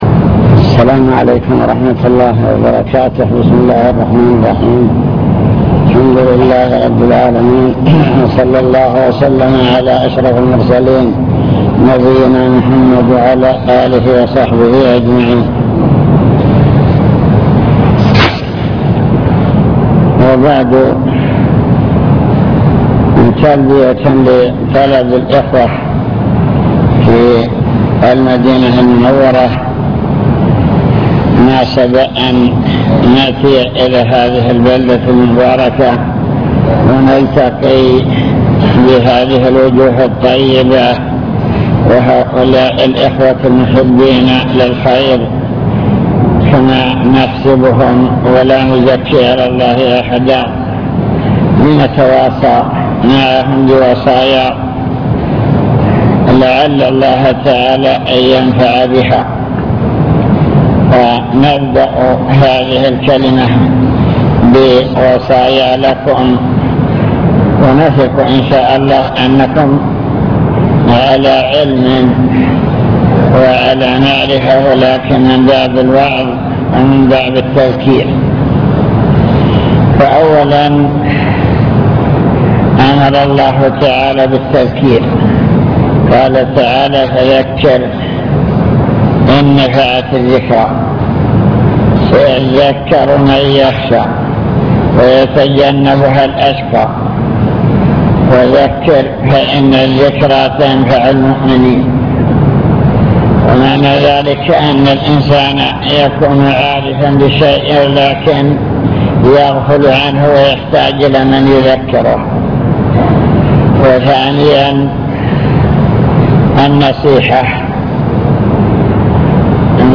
المكتبة الصوتية  تسجيلات - لقاءات  لقاء مفتوح مع الإجابة على الأسئلة